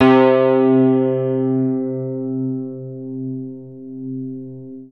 Index of /90_sSampleCDs/Zero G Creative Essentials Series Vol 26 Vintage Keyboards WAV-DViSO/TRACK_21